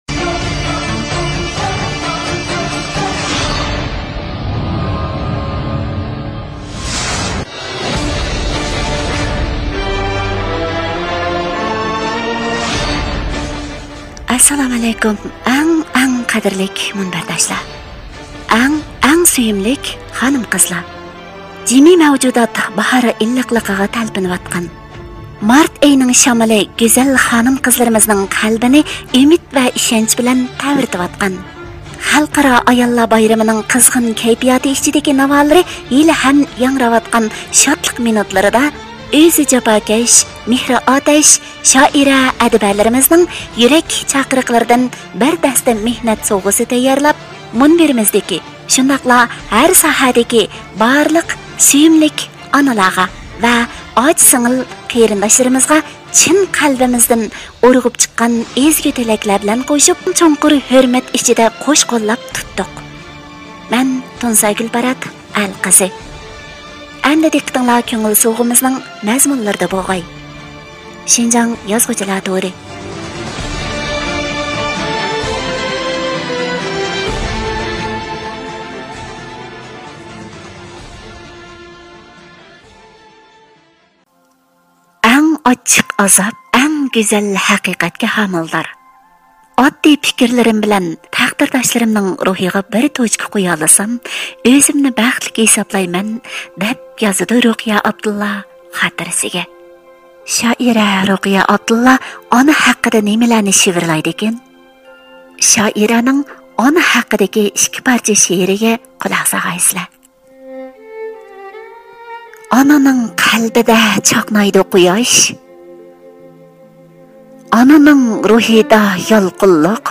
مۇزىكا كىرىشتۈرگۈچى